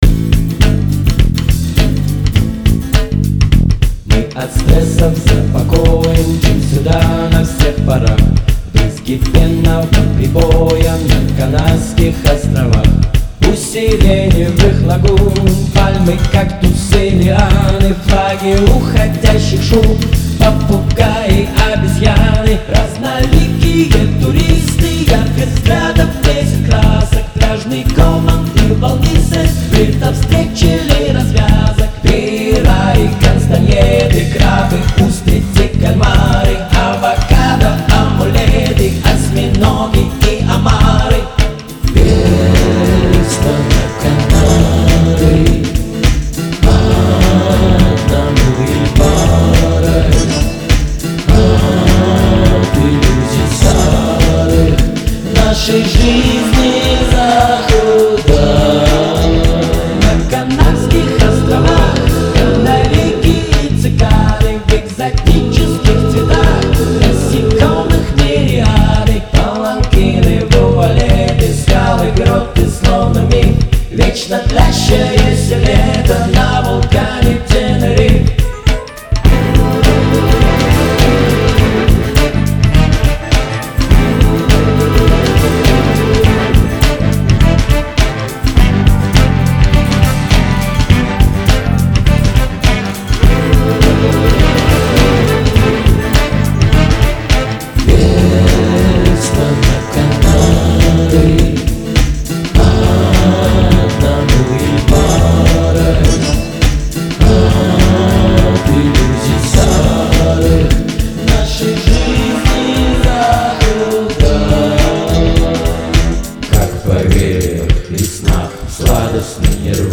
Весело, легко, празднично.
Прелестная, лёгкая, воздушная песенка, увлекает
Better dancing under that song -Samba or Mumba.